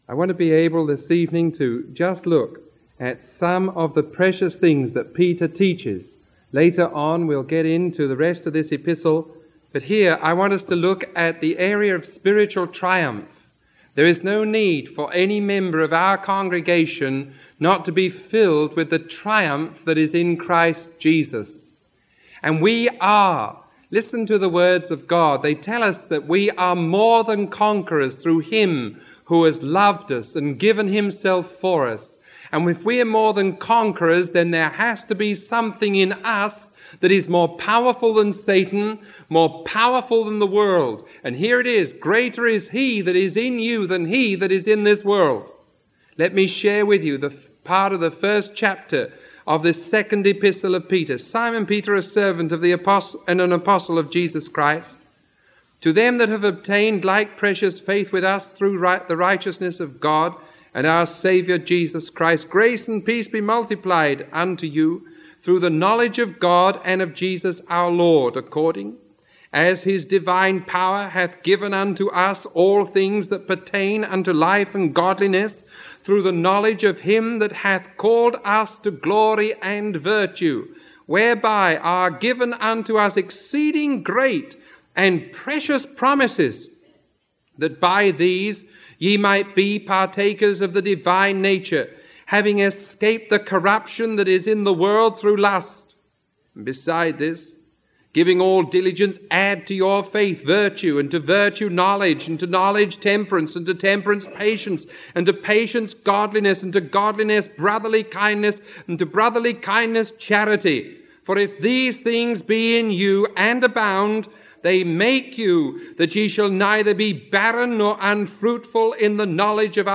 Sermon 0032A recorded on March 18